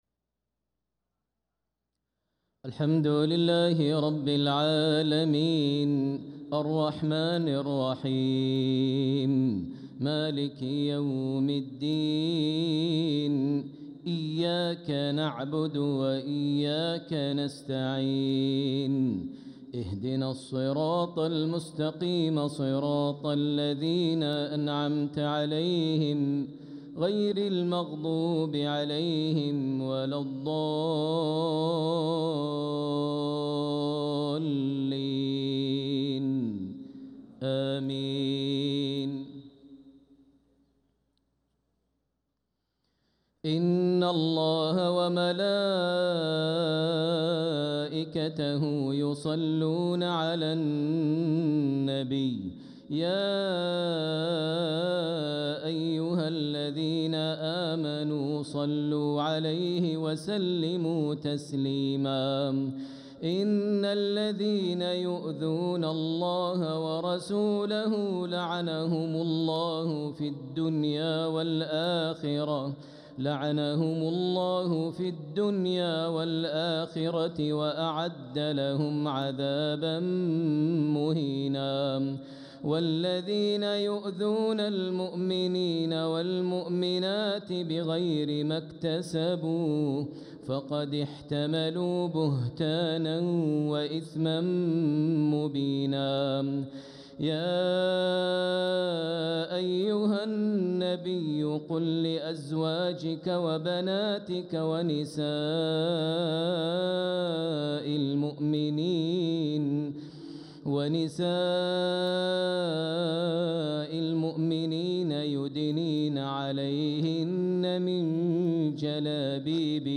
صلاة العشاء للقارئ ماهر المعيقلي 14 ربيع الآخر 1446 هـ
تِلَاوَات الْحَرَمَيْن .